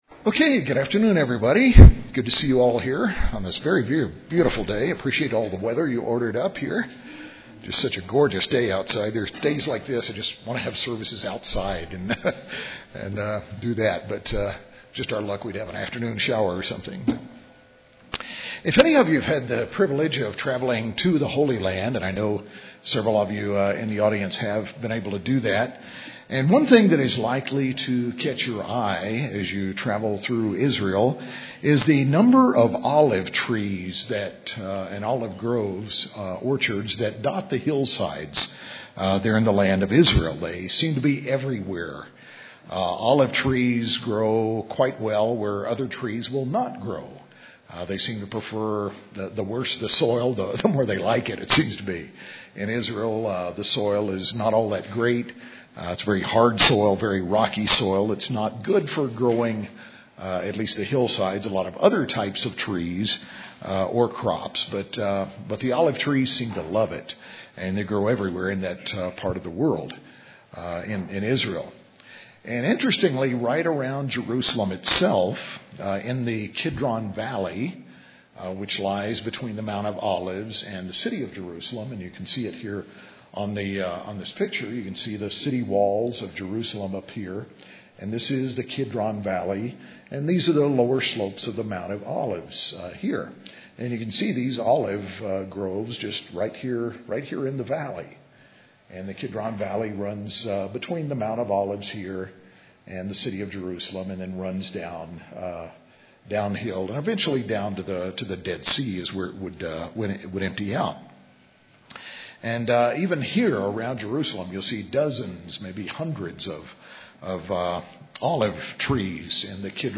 UCG Sermon Gethsemane Transcript This transcript was generated by AI and may contain errors.